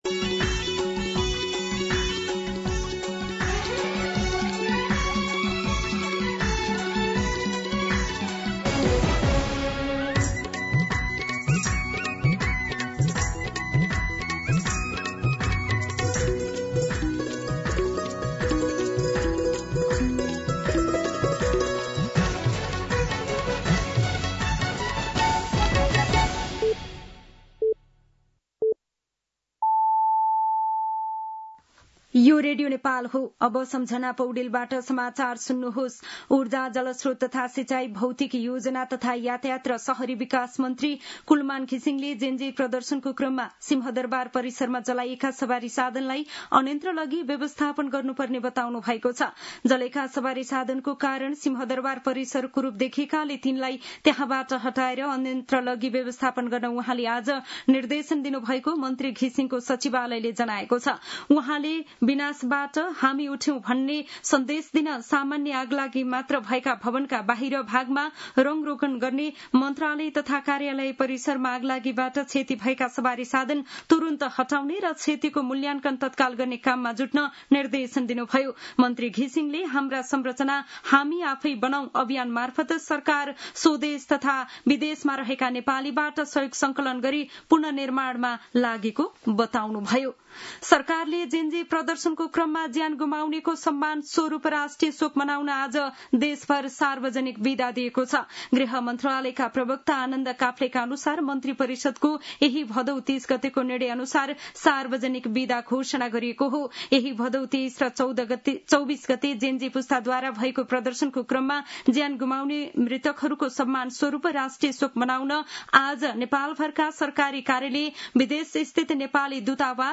दिउँसो ४ बजेको नेपाली समाचार : १ असोज , २०८२
4-pm-Nepali-News-1.mp3